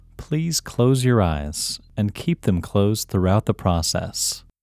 OUT – English Male 1